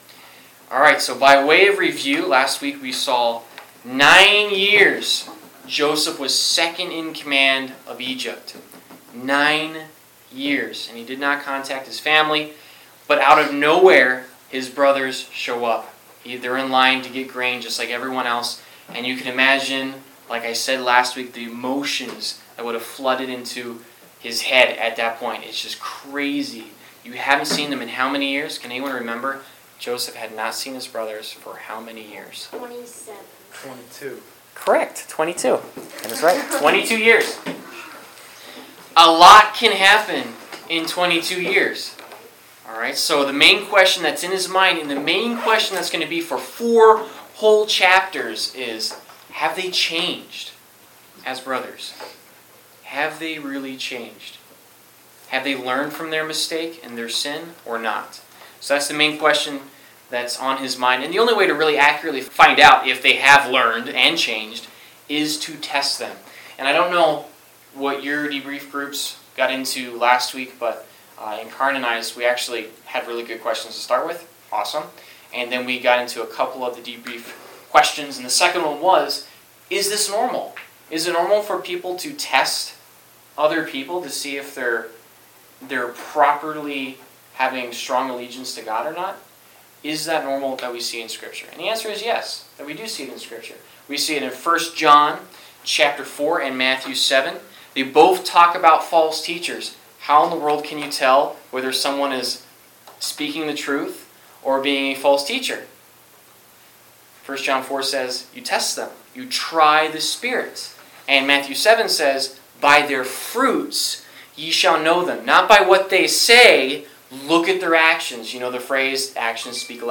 Service Type: Wednesday Night - Youth Group